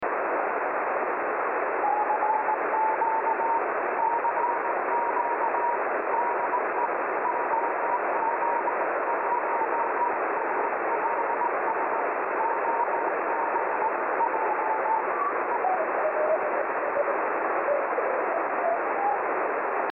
CW
(with my simple transverter setup 8db NF)